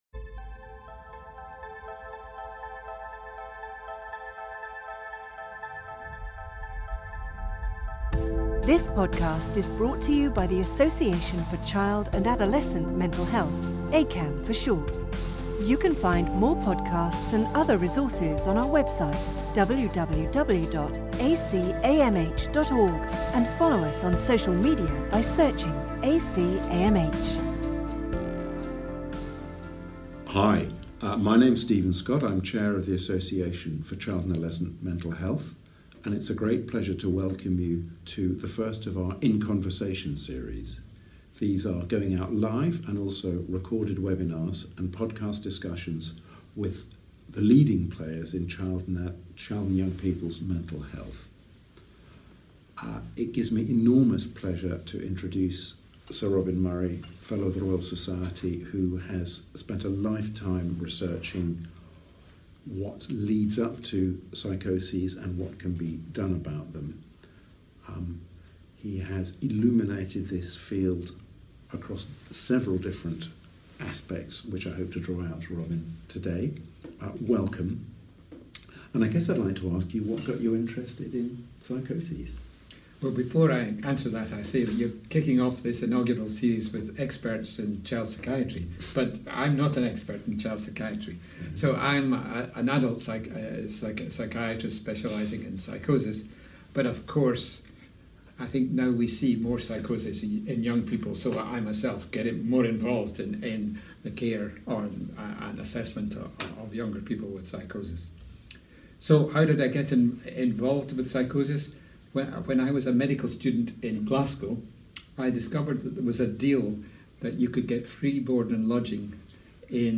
In Conversation... Psychosis